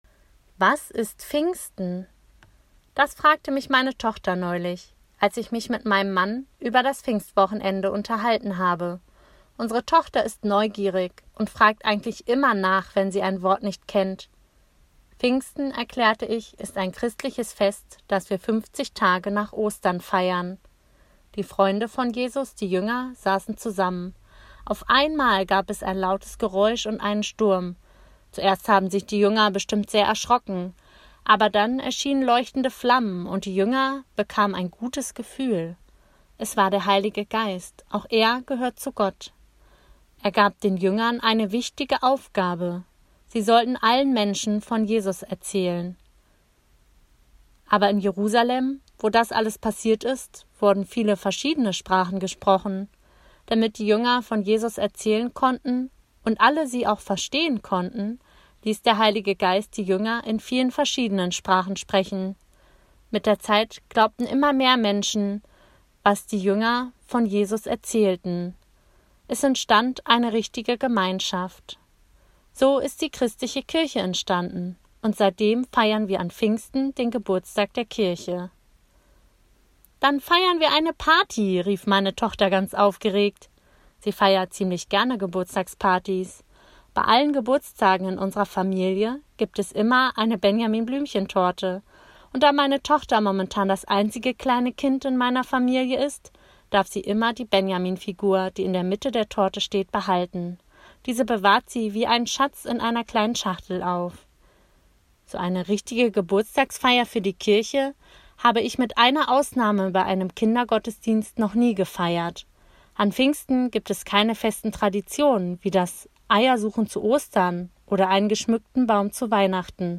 Andacht zum 31. Mai, Pfingmontag